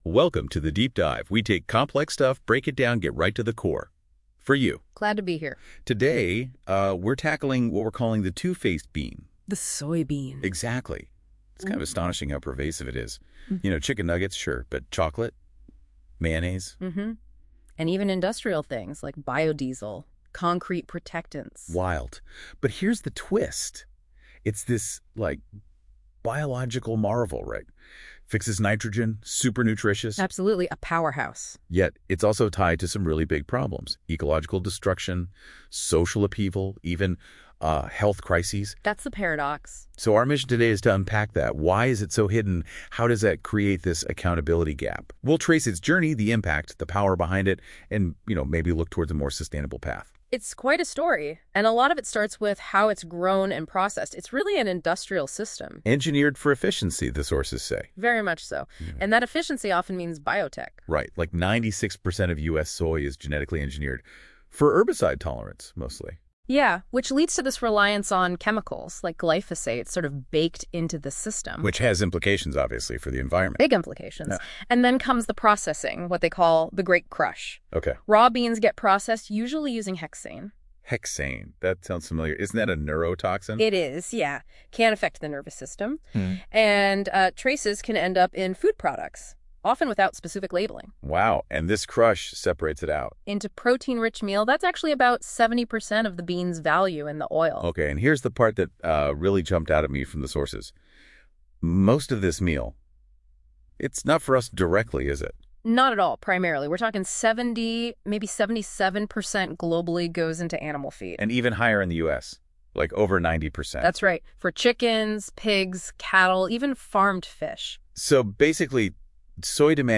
Listen to our 6-minute conversation about this articles content in our Deep Dive if you are short of time